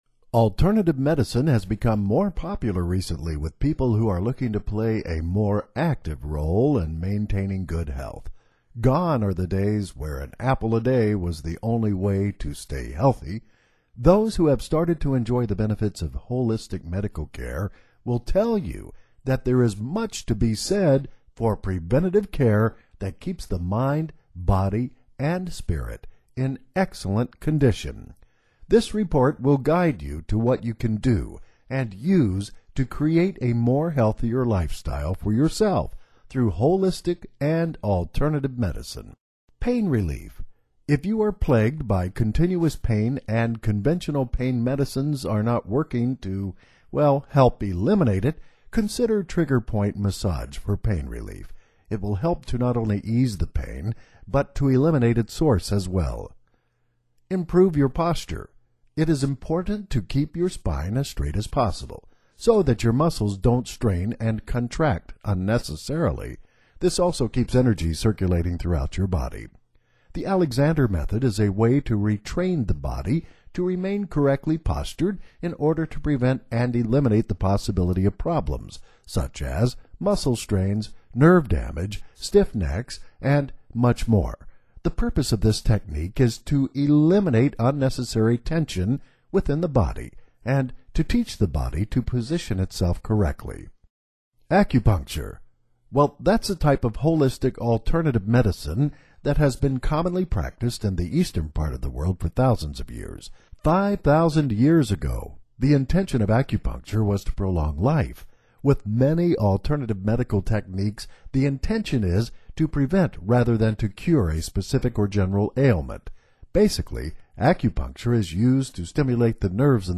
This narrated guide shares simple, effective natural remedies to support your health, ease discomfort, and reduce stress. From herbal teas to kitchen staples, learn how to feel better using what you already have — no noise, just results.